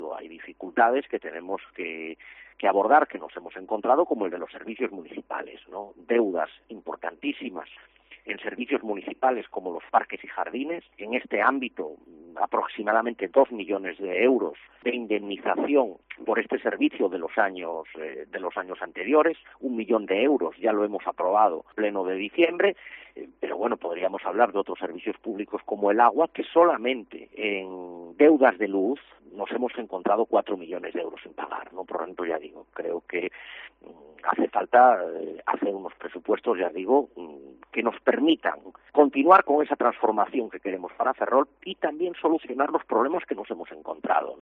José Manuel Rey Varela en los estudios de COPE Ferrol
El alcalde de Ferrol, José Manuel Rey Varela, recordó en los micrófonos de COPE Ferrol que los presupuestos de este año 2024 todavía no están aprobados por "diferentes cuestiones"; entre ellas la falta de partidas de los presupuestos generales del Estado o de problemas heredados del anterior gobierno socialista.